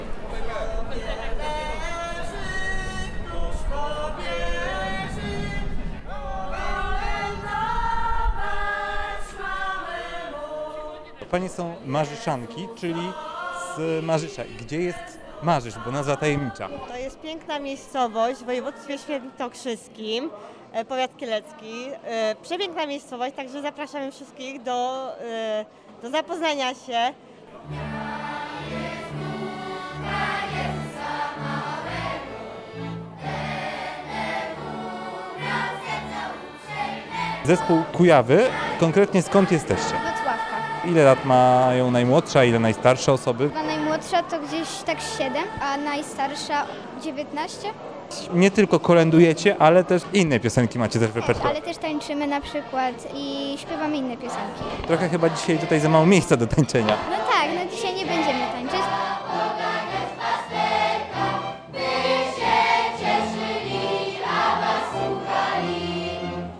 Muzyka, ozdoby świąteczne oraz regionalne potrawy – to główne atrakcje Jarmarku Świątecznego, który odbył się w Narodowym Instytucie Kultury i Dziedzictwa Wsi na Krakowskim Przedmieściu w Warszawie.
Przed budynkiem, a także wewnątrz można było usłyszeć ludowe kolędy w wykonaniu zespołu Marzyszanki z Marzysza w województwie świętokrzyskim oraz dziecięco-młodzieżowej części zespołu Kujawy.